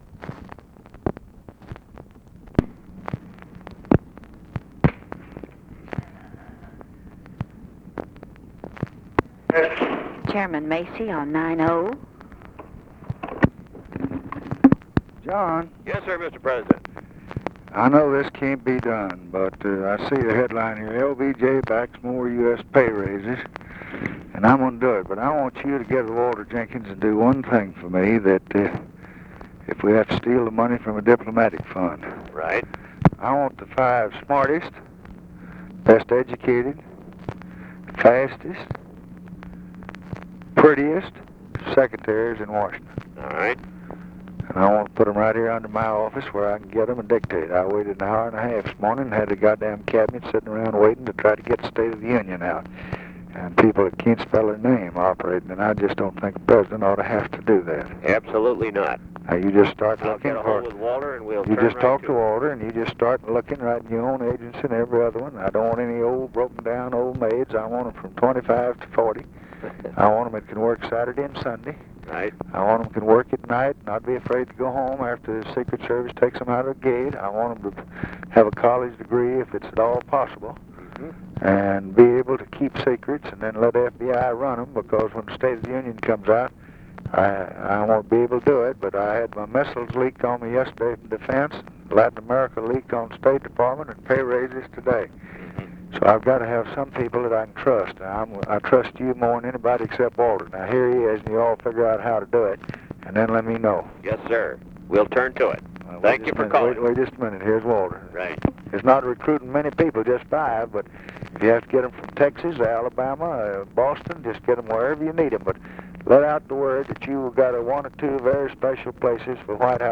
Conversation with JOHN MACY and WALTER JENKINS, January 6, 1964
Secret White House Tapes